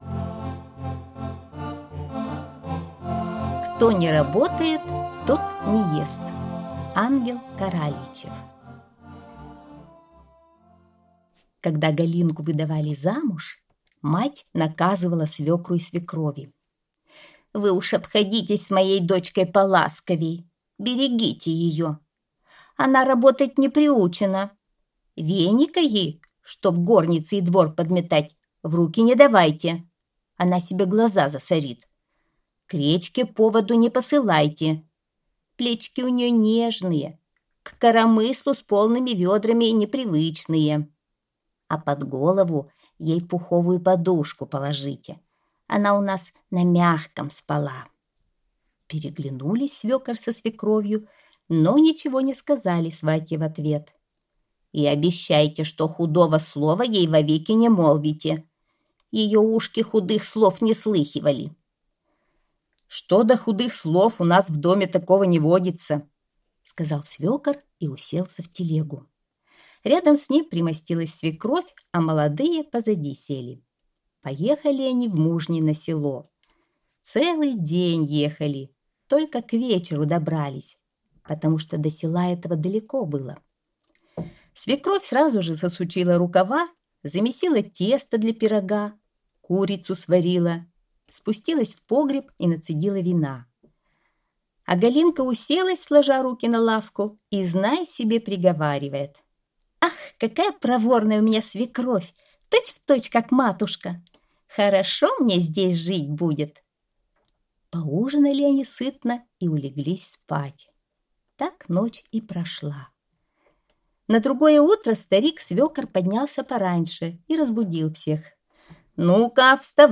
Кто не работает, тот не ест - аудиосказка Ангела Каралийчева - слушать онлайн | Мишкины книжки